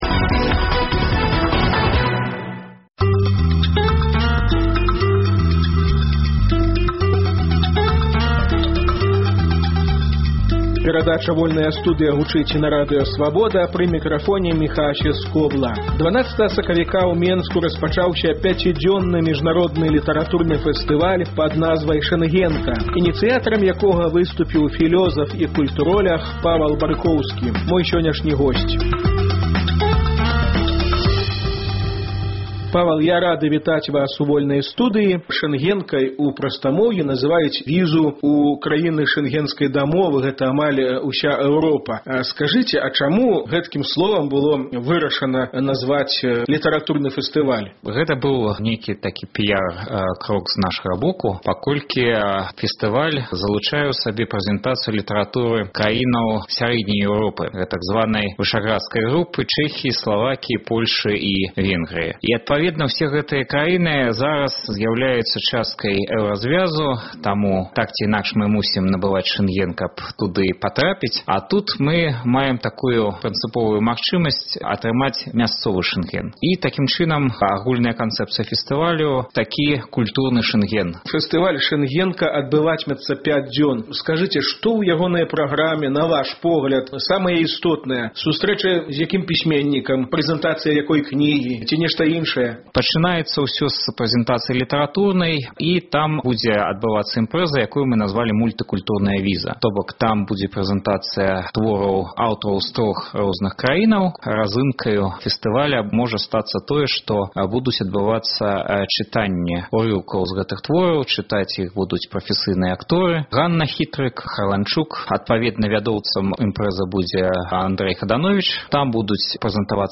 Госьць праграмы -- культуроляг